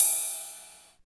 59 RIDE CYM.wav